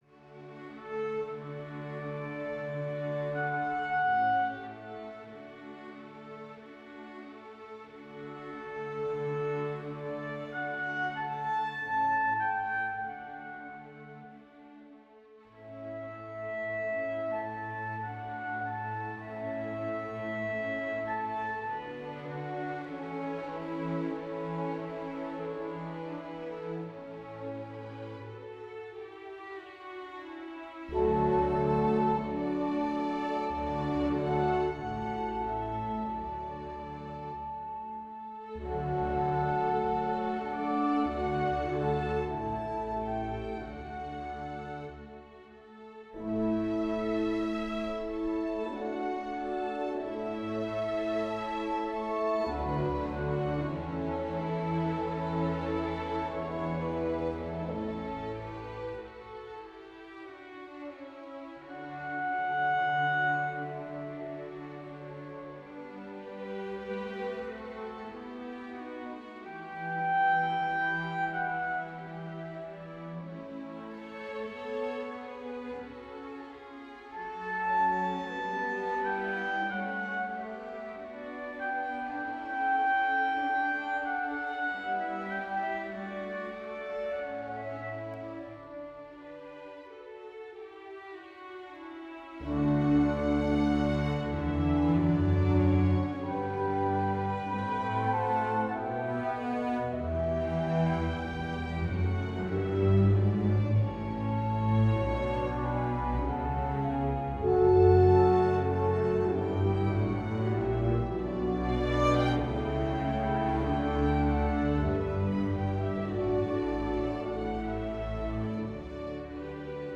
Here's the piece rendered in StaffPad: The first is using the Berlin libraries (strings, woodwinds and brass), the second is using the MuseScore libraries. There's an unfortunate sounding glitch in the Musescore version...just a heads up.